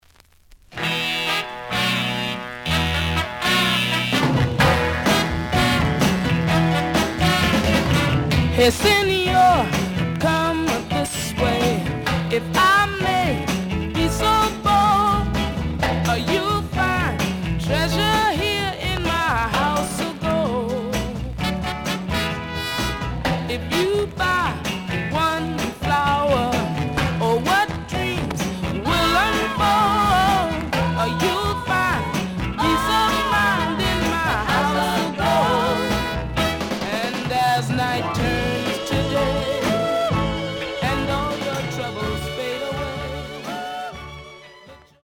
The audio sample is recorded from the actual item.
●Genre: Soul, 60's Soul
Some periodic noise on B side due to scratches.